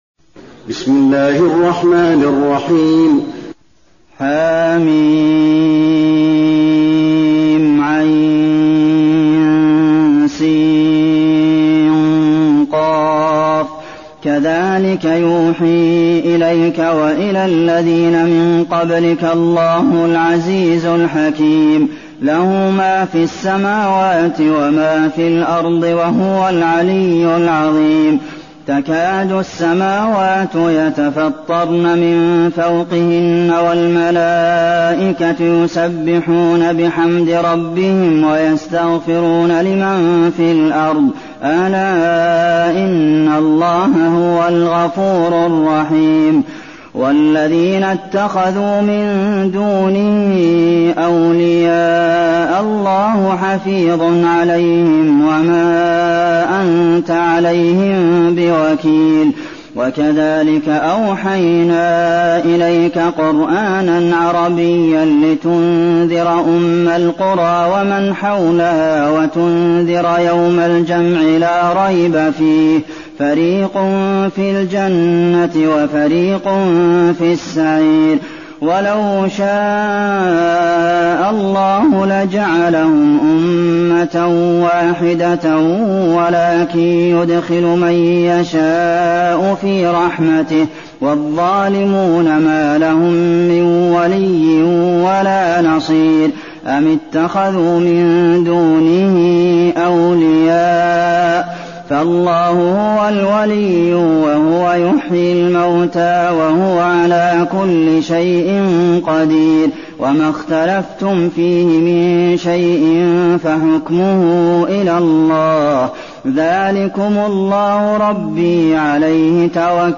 المكان: المسجد النبوي الشورى